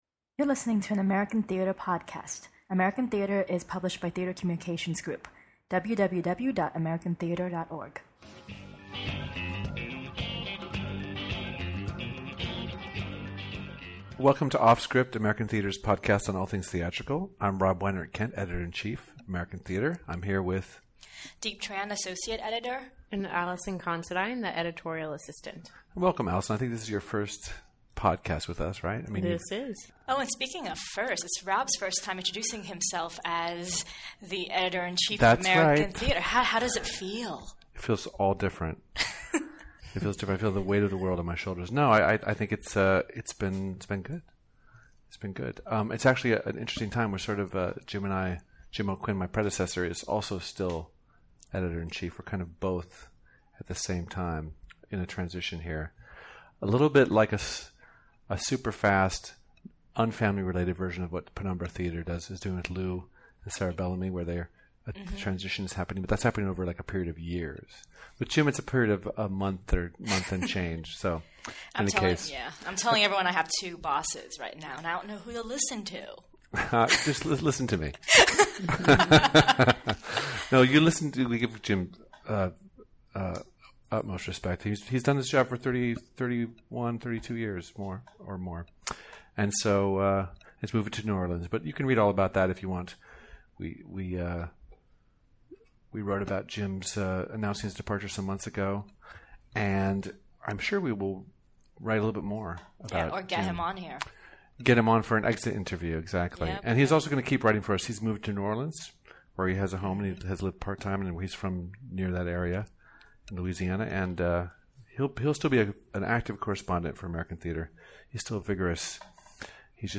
Every other week, the editors of American Theatre curate a free-ranging discussion about the lively arts in our Offscript podcast.